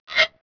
scrape0e.wav